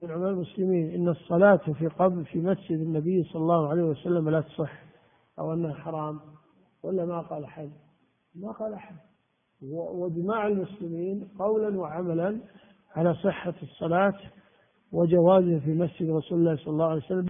مقطع شرح حديث 776